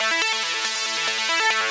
Index of /musicradar/shimmer-and-sparkle-samples/140bpm
SaS_Arp05_140-A.wav